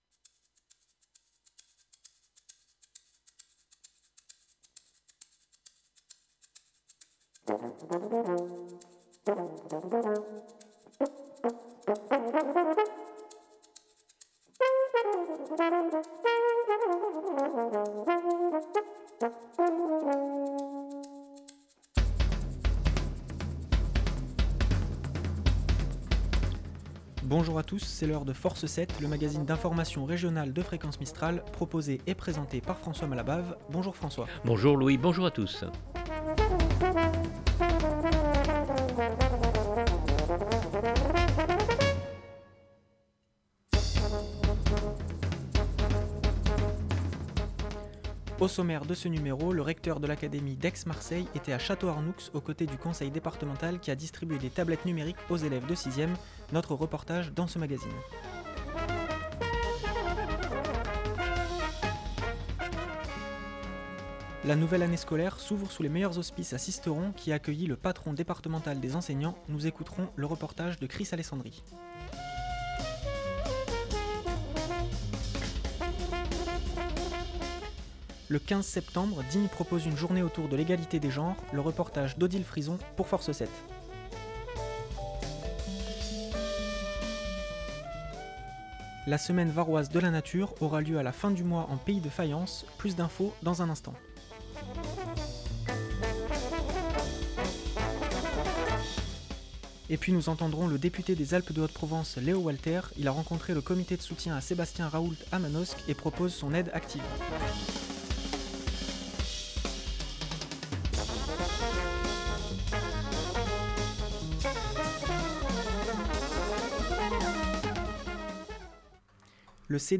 -Et puis nous entendrons le député des Alpes de Haute-Provence Léo Walter.